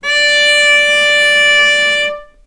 vc-D5-ff.AIF